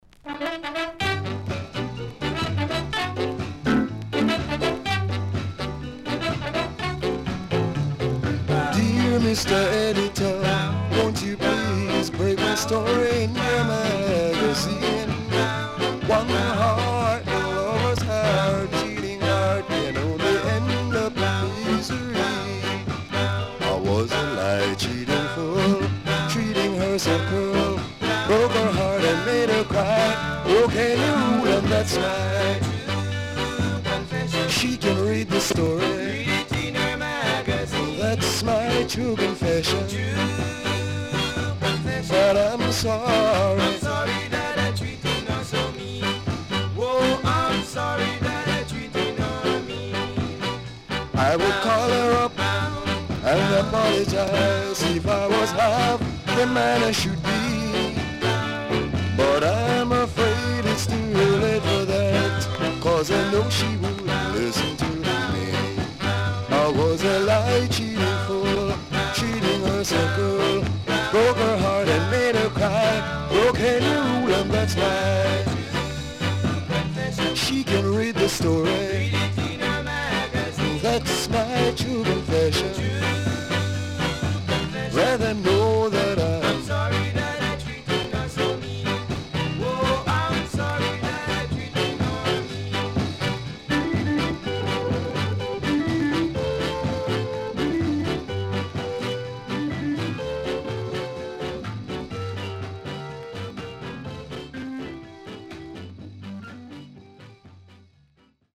HOME > SKA / ROCKSTEADY
Authentic Ska Inst & Good Ska Vocal.W-Side Good
SIDE A:所々チリノイズがあり、少しプチノイズ入ります。